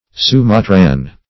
Sumatran \Su*ma"tran\, a.